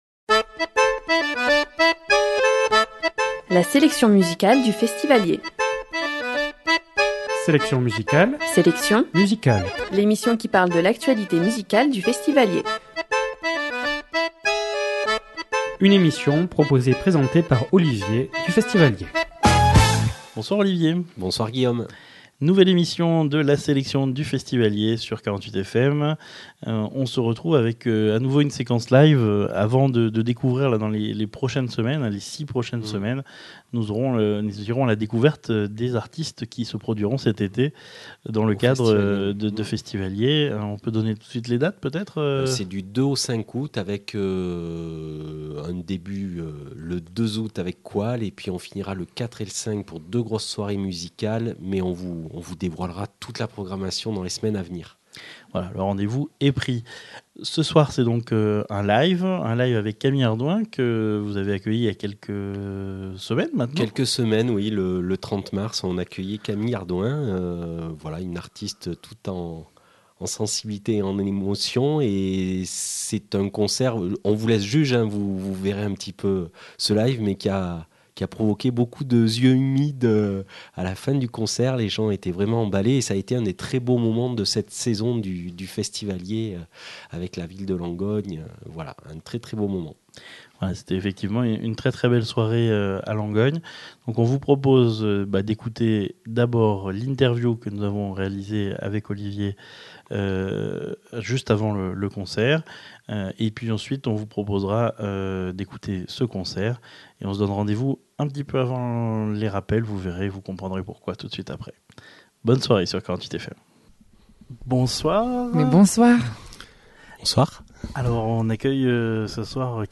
Séquence « Live » enregistrée dans le cadre de Festiv’Allier la saison